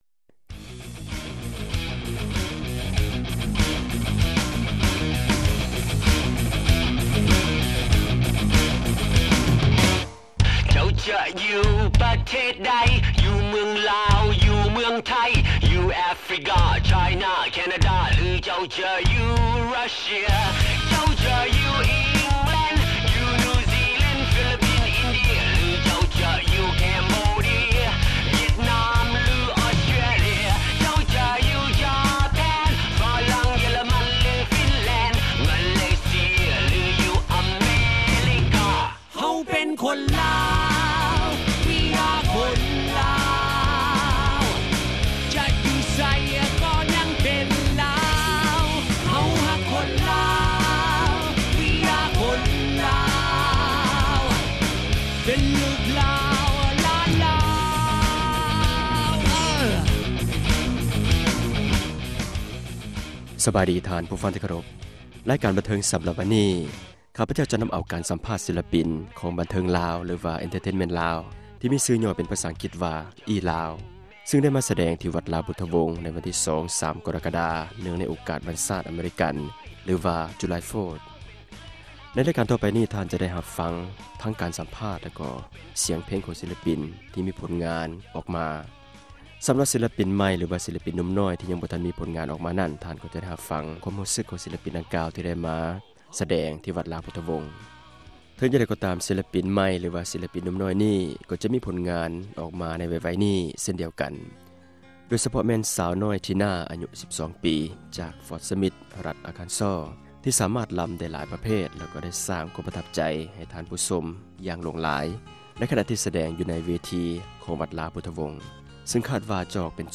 ສໍາພາດ ສິລປິນ ບັນເທີງລາວ ຫລື ELao ທີ່ມາສະແດງ ຢູ່ວັດລາວ ພຸດທະວົງ ໃນວັນທີ 2-3 ກໍຣະກະດາ.